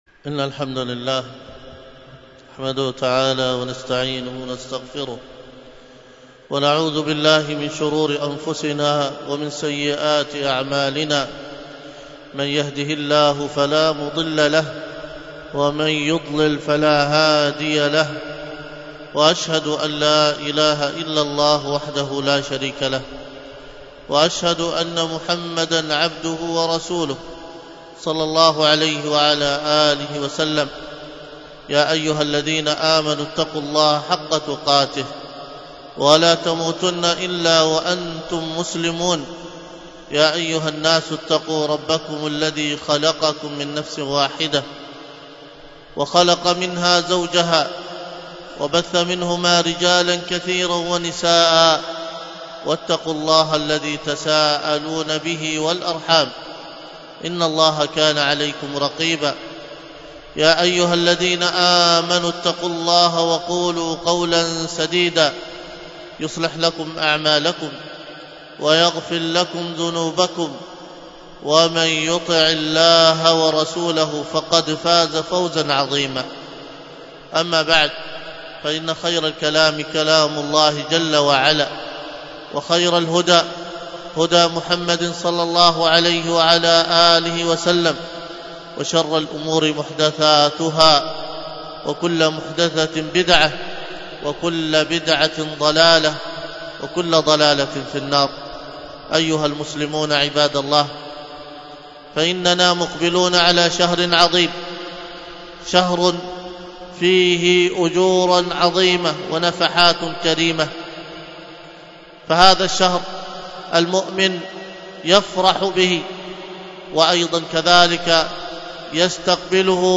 الخطبة بعنوان مقومات بناء الأسرة - حق الزوج، والتي كانت بمسجد السنة بدار الحديث بطيبة